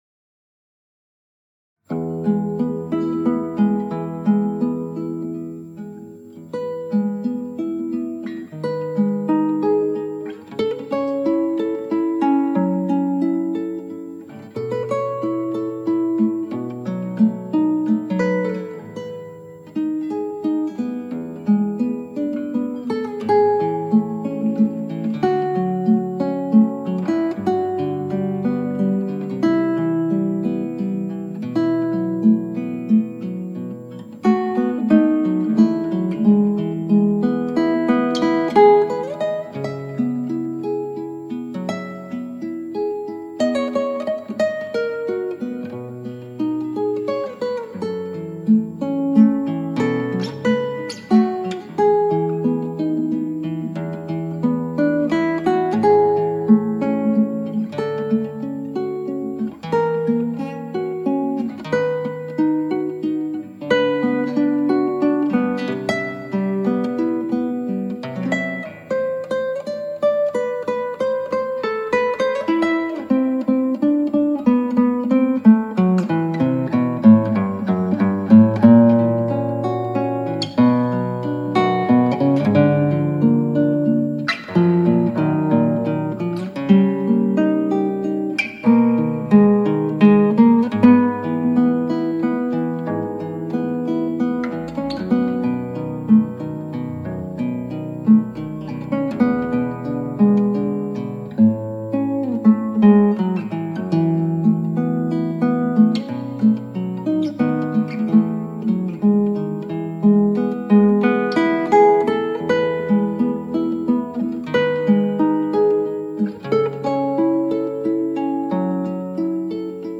クラシックギター　ストリーミング　コンサートサイト